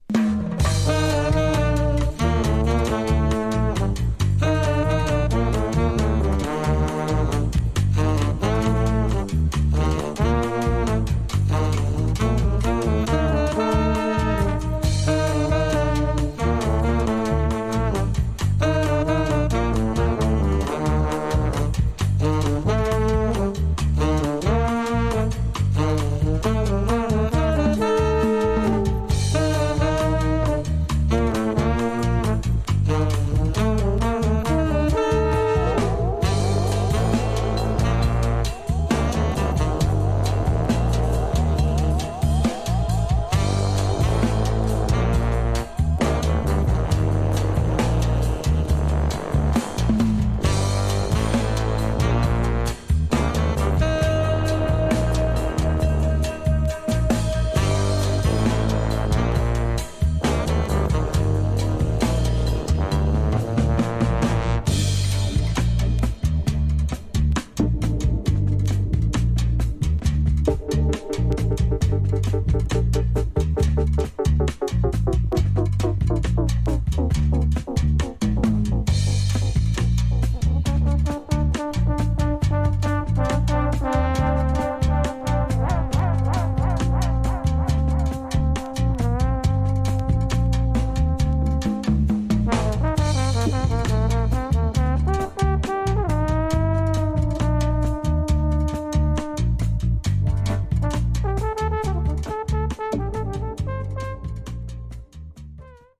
Tags: Brass , Psicodelico , Colombia , Bogotá